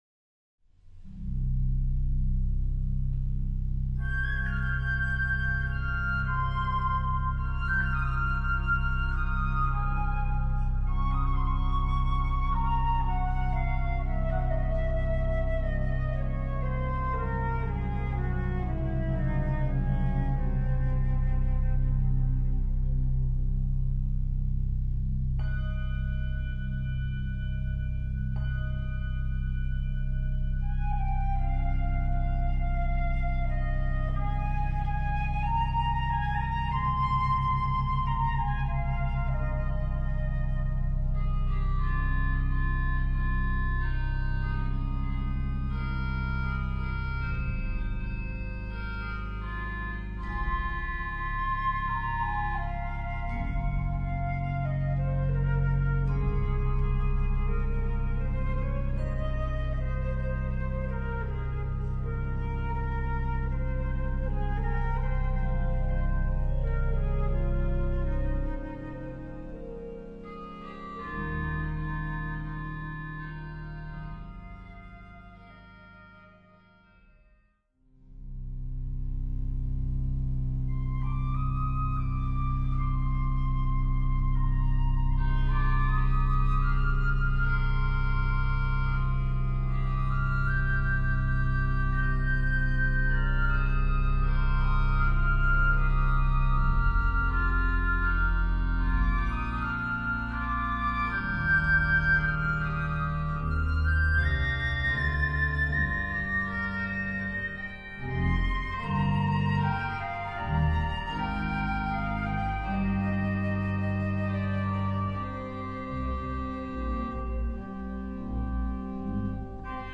Voicing: Instrument and Organ